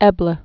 (ĕblə)